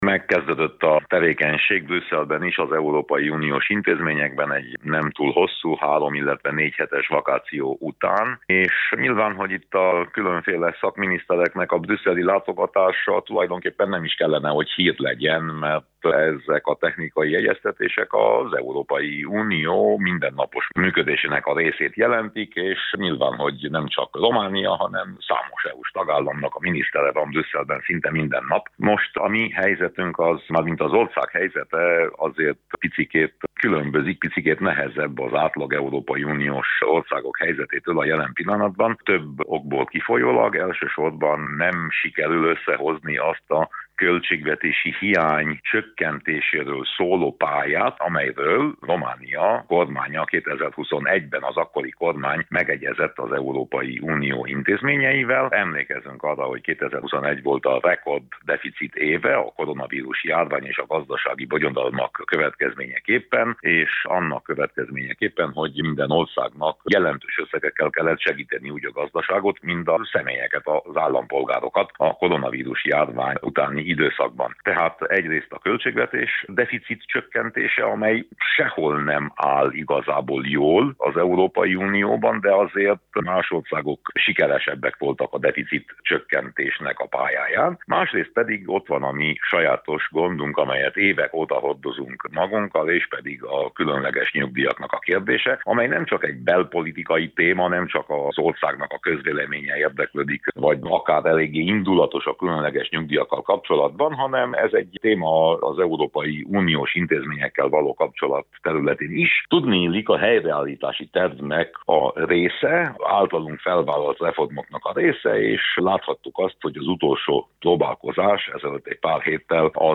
Winkler Gyula európai parlamenti képviselővel beszélgetett a téma kapcsán